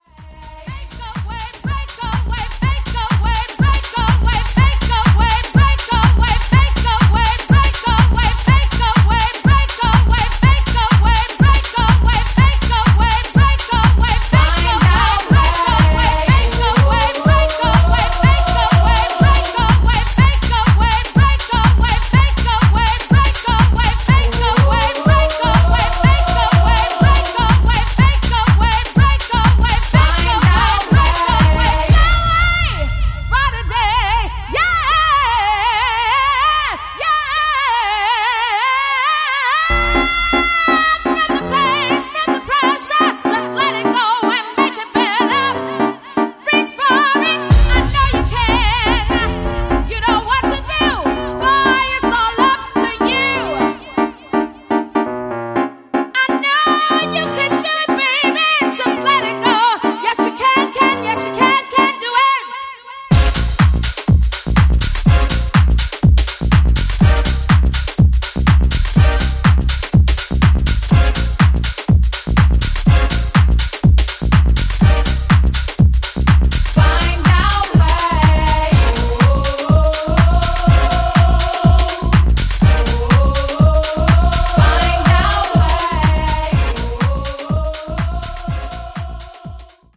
Four classic US house vocals.